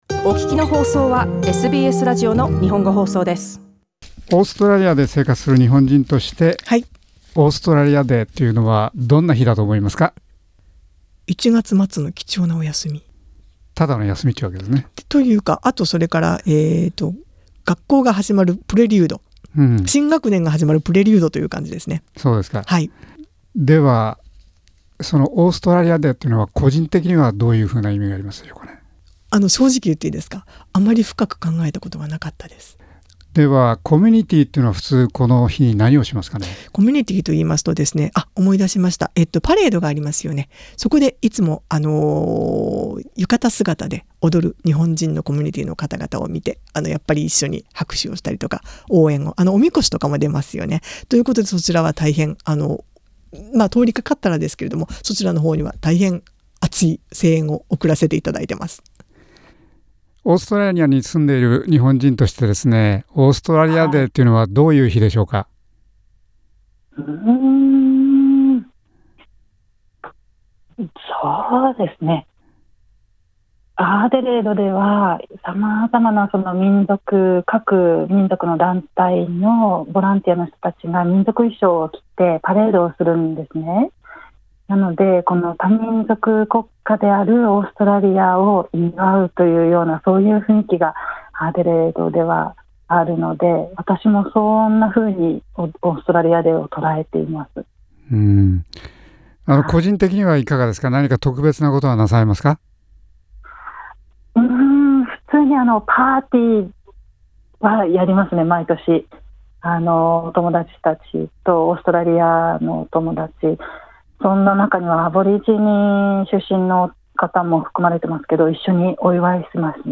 オーストラリアに永住している日本人のみなさんにオーストラリアデーはどんな日で、何をすることが多いかを聞いてみました。